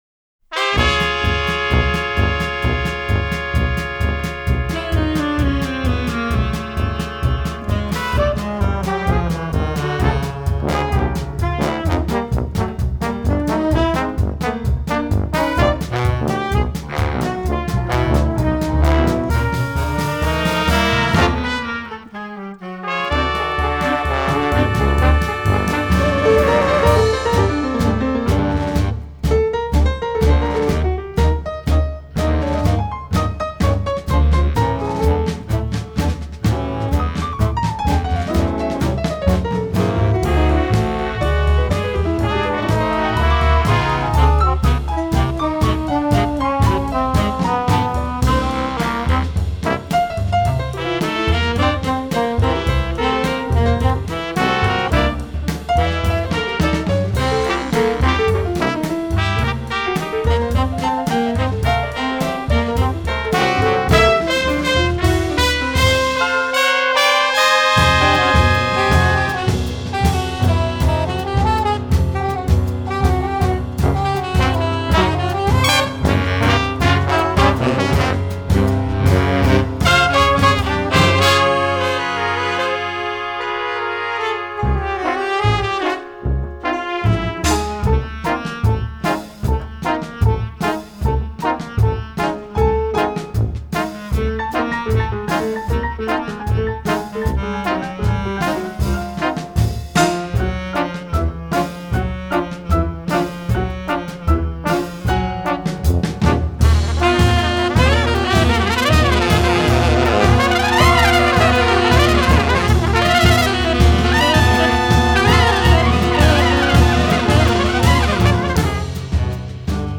Soprano Sax & Flute
Tenor Sax & Clarinet
Bass Clarinet & Baritone Sax
Trumpet
Bass Trombone
Tuba
Piano
Drums